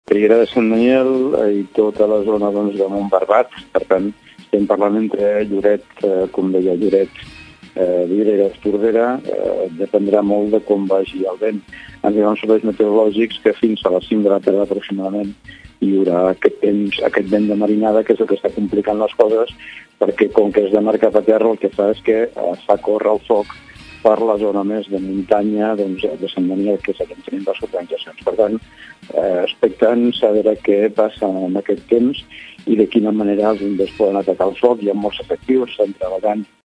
alcalde-incendi-blanes-2.mp3